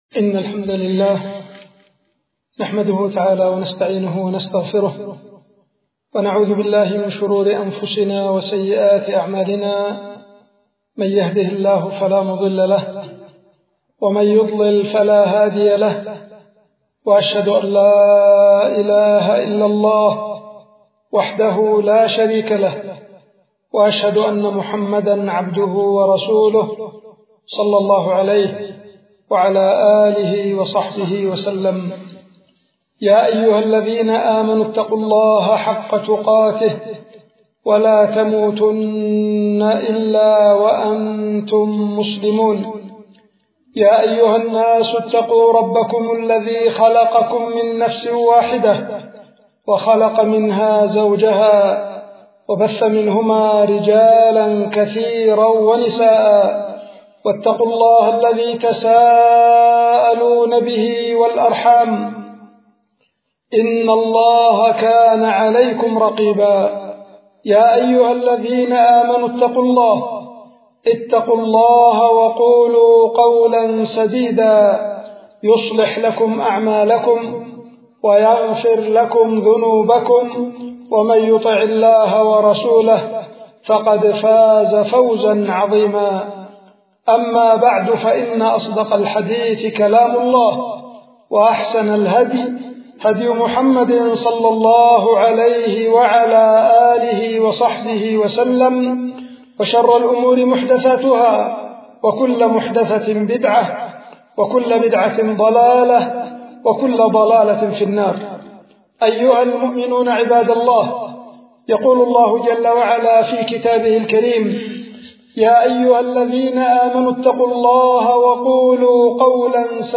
القيت في مسجد العدنة في العزلة- بعدان
خطبة